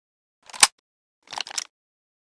ppsh41_unjam.ogg